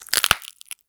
High Quality Footsteps
STEPS Glass, Walk 07.wav